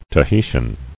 [Ta·hi·tian]